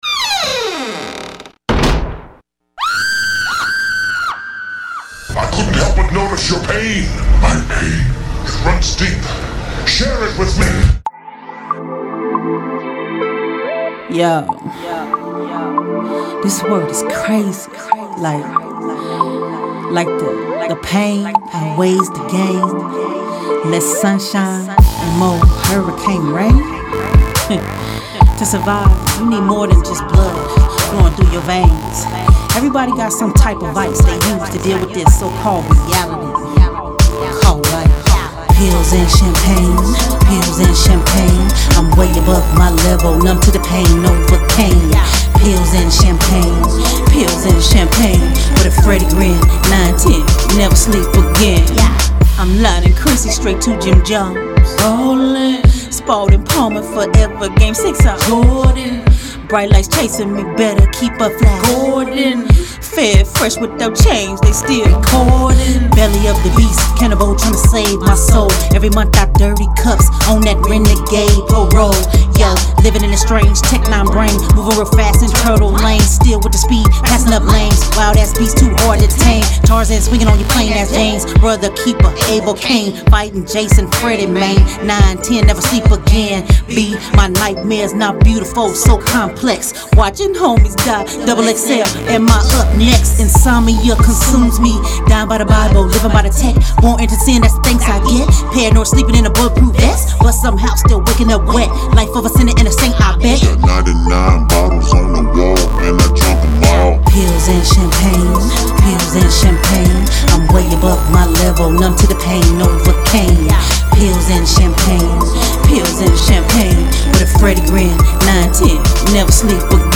german rappers
dope instrumental